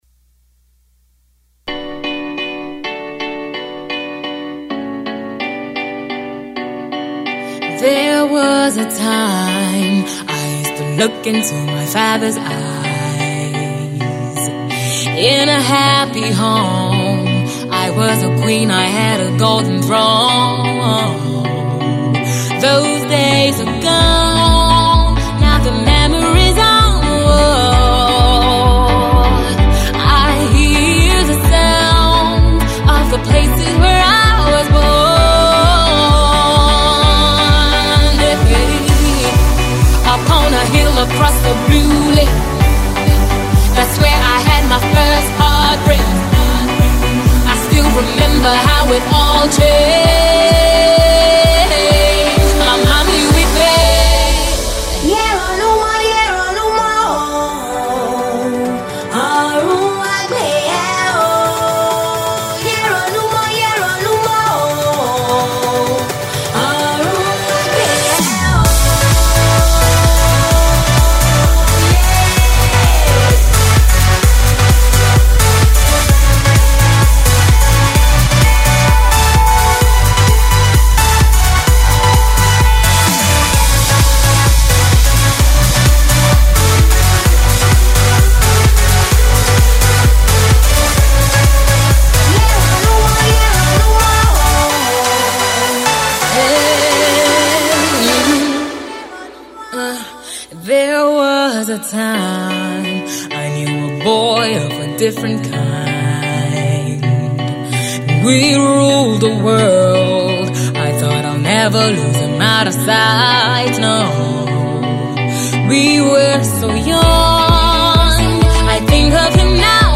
remix/cover
Check out the new Yoruba chorus.